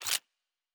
pgs/Assets/Audio/Sci-Fi Sounds/Weapons/Weapon 05 Foley 3 (Laser).wav at master
Weapon 05 Foley 3 (Laser).wav